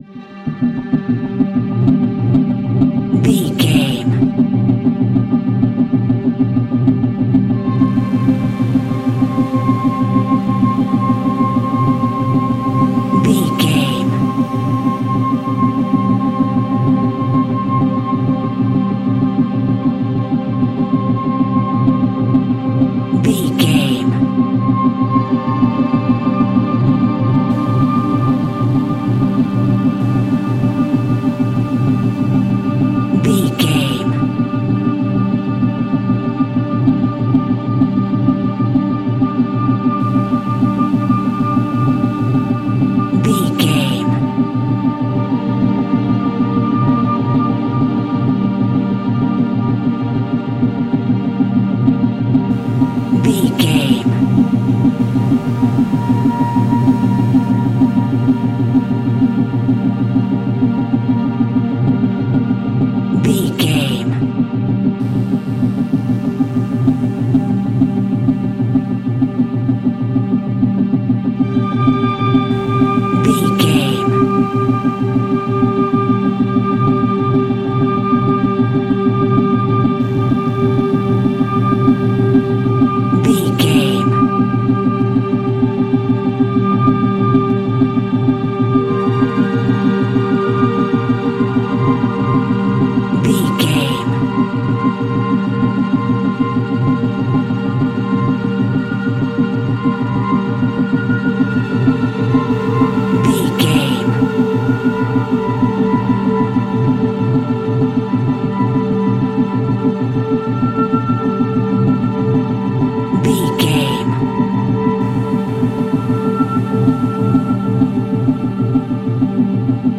In-crescendo
Thriller
Aeolian/Minor
ominous
dark
suspense
eerie
strings
synth
ambience
pads